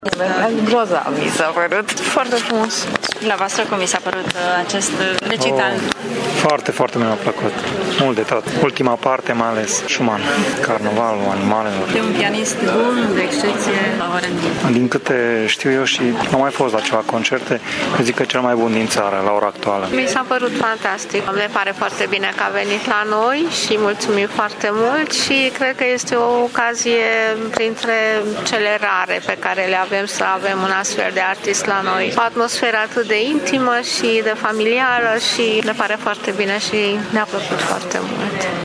Târgumureșenii au fost extrem de încântați de acest recital cu atât mai mult cu cât Horia Mihail este considerat cel mai bun pianist al României: